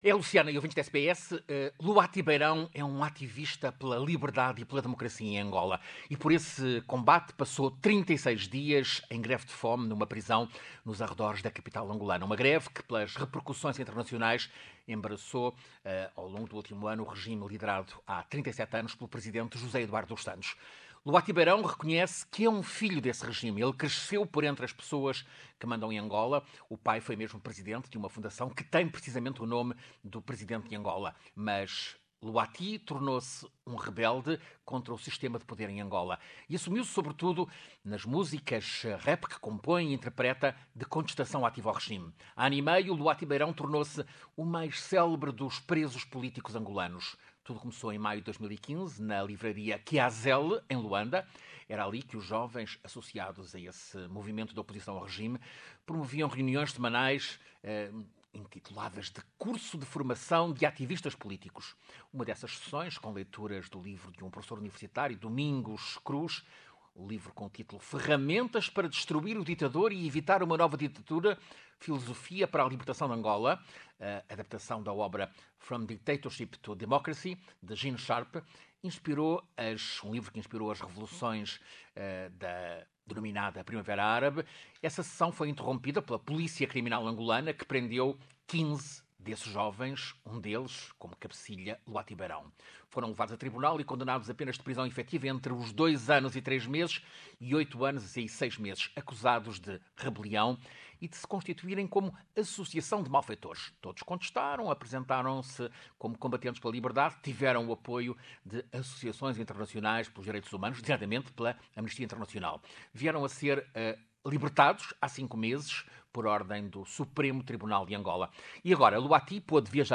Beirão lança seu diário de prisão em Lisboa no dia em que é anunciada a sucessão presidencial em Angola. Ouça reportagem